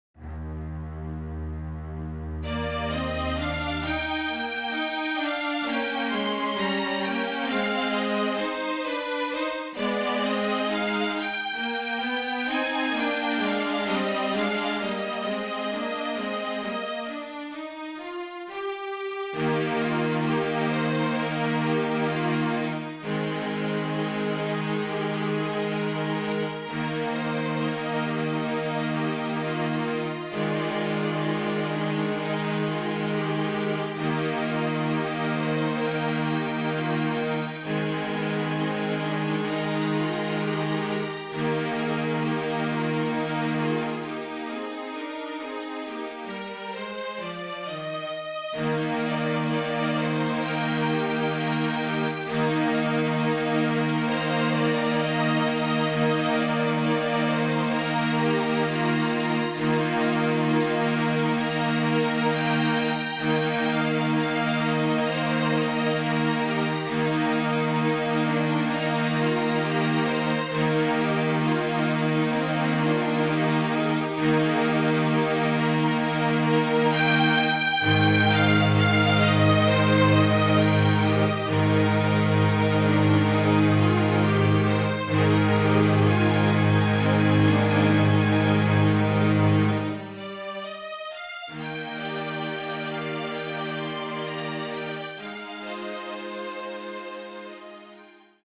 Flute, Violin and Cello (or Two Violins and Cello)
MIDI